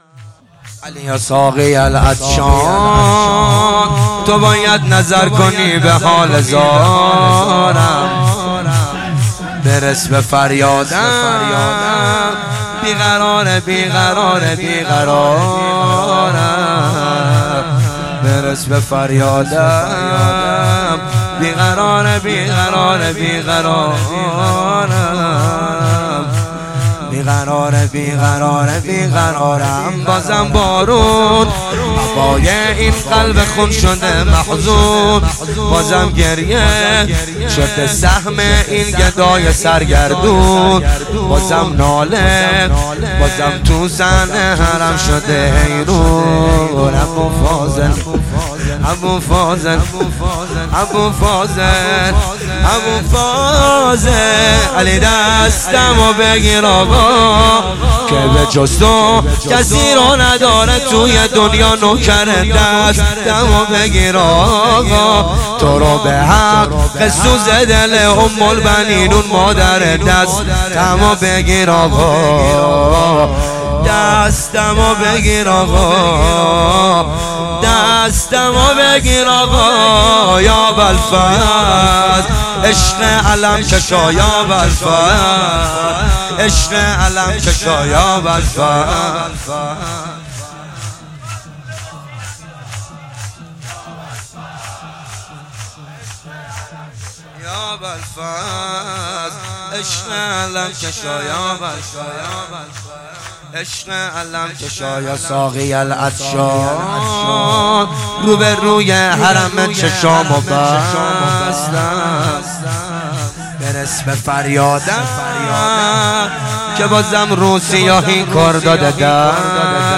شور
دهه اول صفر 1441 شب سوم